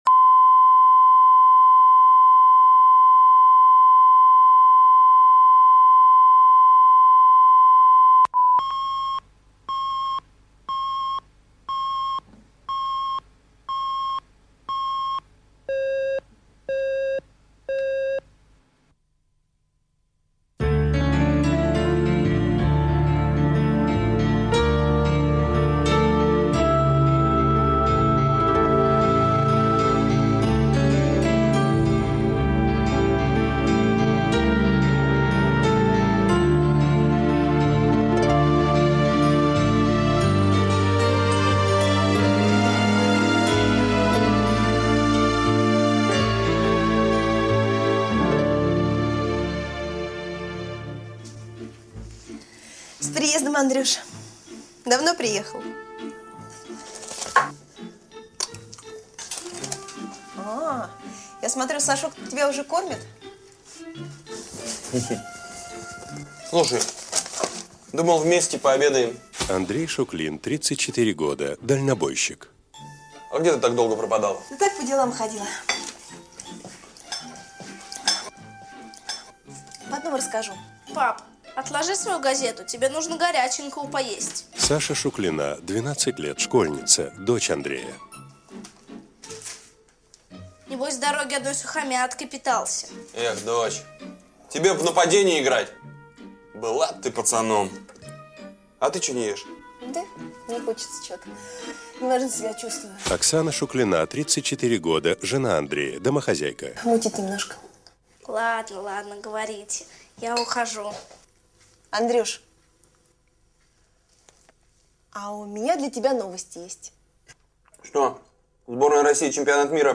Аудиокнига Девичий переполох